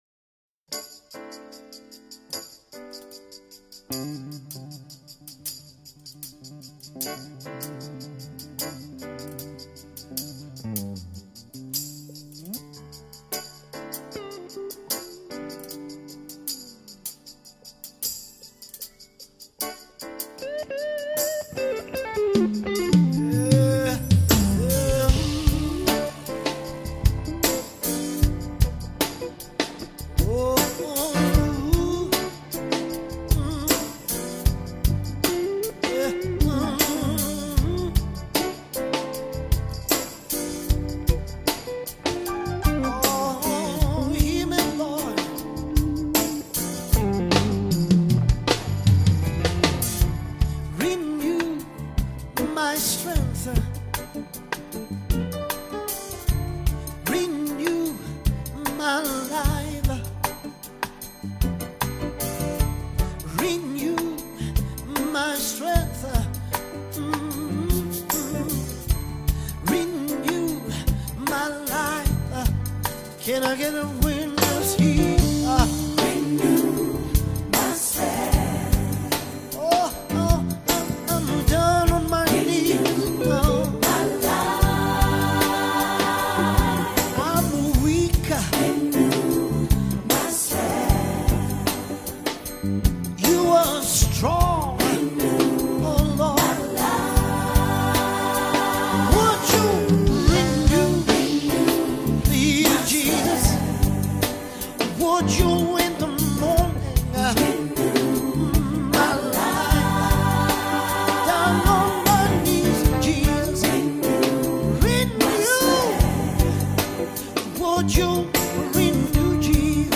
Latest 2024 Live South African Worship Song